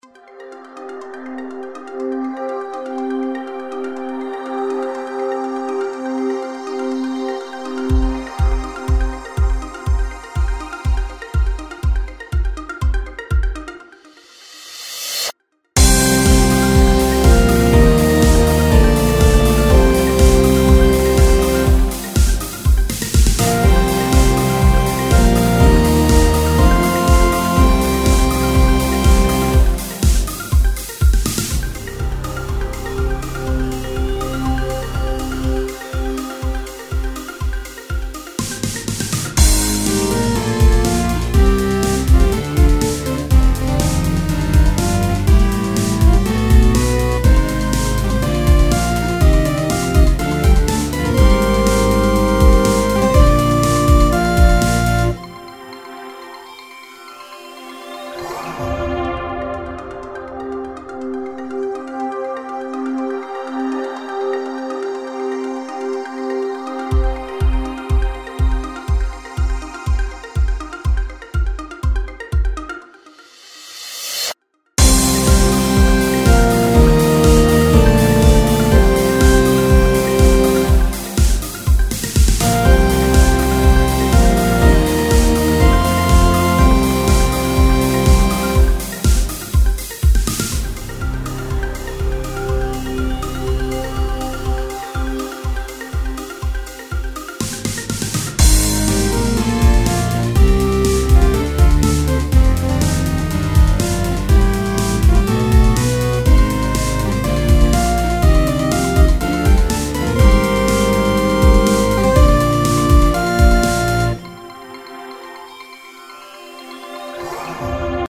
EDMニューエイジ暗い激しい